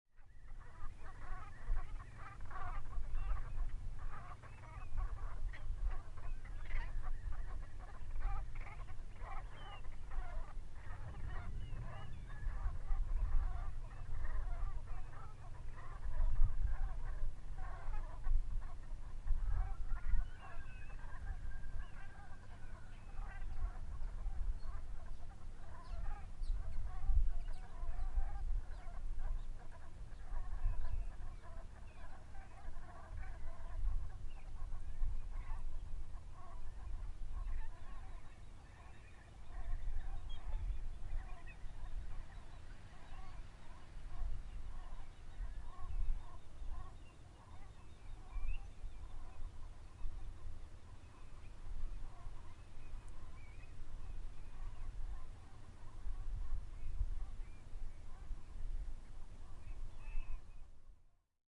Gaggle Of Brent Geese Bouton sonore